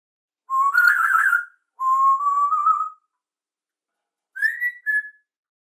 54 Hörproben "Vogelstimmen der Welt/Exotische Vogelwelt
Graupapagei - Psittacus erithacus (*)
Graupapagei_Psittacus_erithacus_AMPLE-EXVW107.mp3